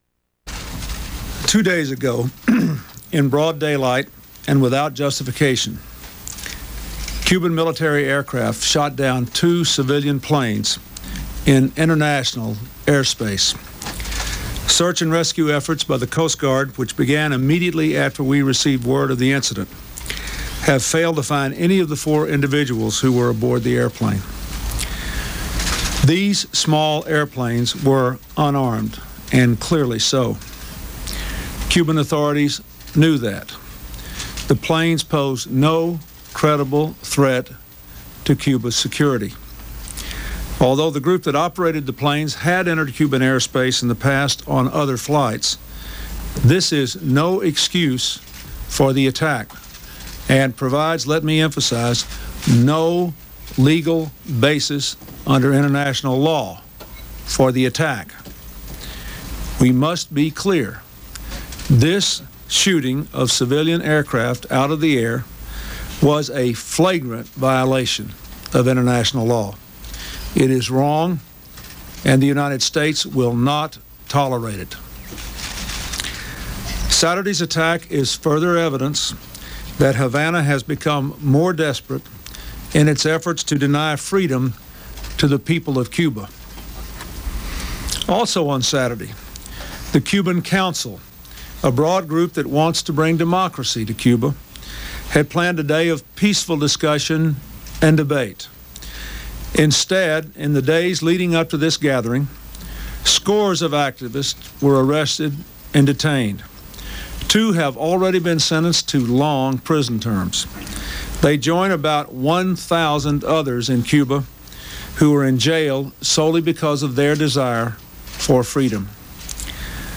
U.S. President Bill Clinton announces six retaliatory sanctions for the "Brothers to the Rescue" planes shot down by the Cuban Air Force